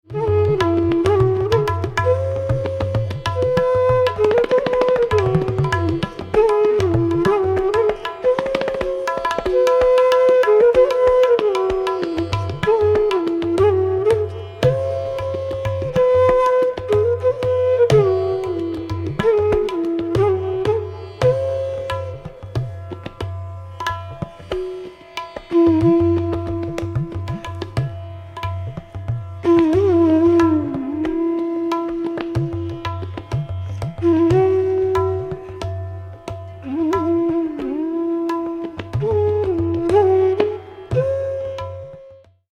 Aroha: SRGMPdNS
Avroh: SNdPMGRS
Pakad: variable, e.g. GRGMPdP, MGRSNS
—Hariprasad Chaurasia (~1990s)—
[gat, e.g. 4:33] GSRG, (P)d dP, GM(Pd)P (M)GR (SN)S; GSRG, (P)d dP…
Jazz: Lydian b6
• Tanpura: Sa–Pa (+dha)